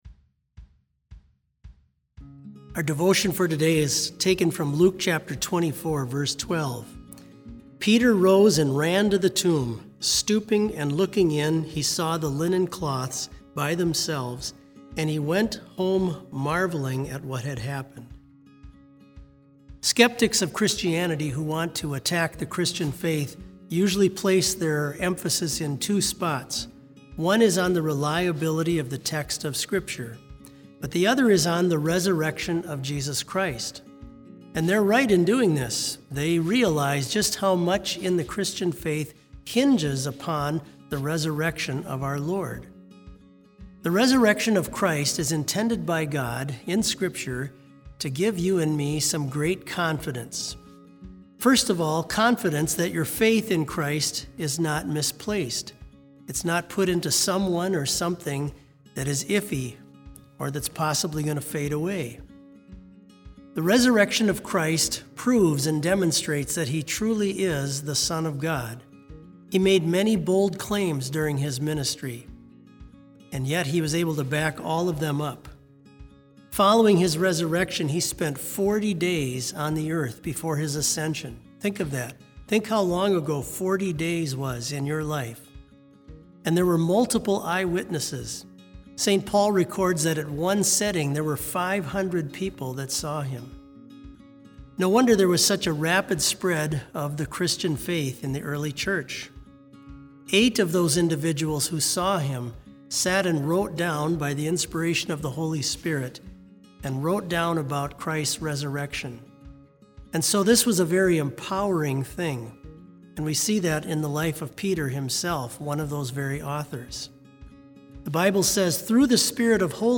Complete service audio for BLC Devotion - April 20, 2020